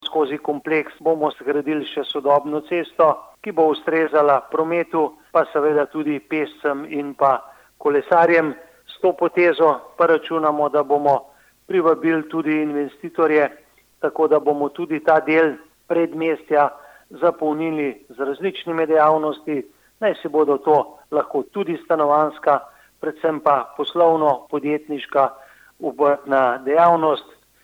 izjava_mag.borutsajoviczupanobcinetrzic_cestabpt.mp3 (670kB)